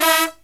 HIGH HIT03-R.wav